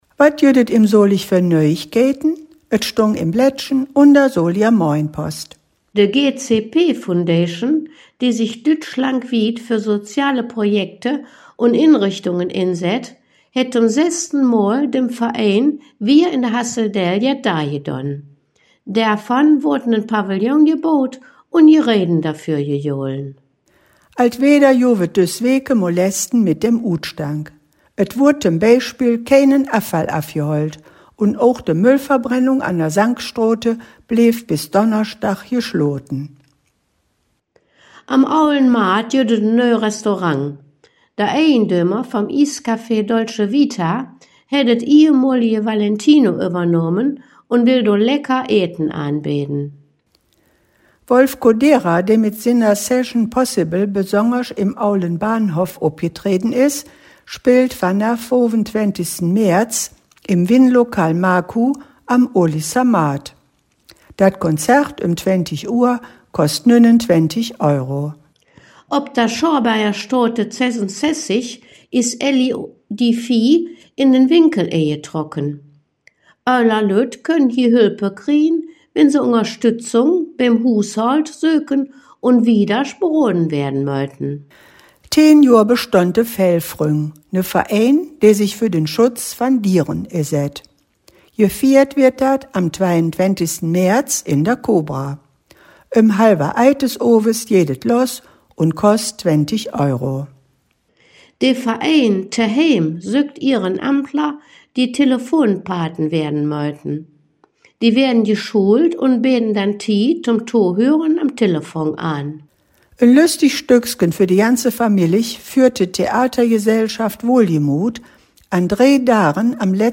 In dieser Folge "Dös Weeke em Solig" blicken de Hangkgeschmedden in Solinger Platt auf die Nachrichten vom 07. März 2025 bis zum 14. März 2025 zurück.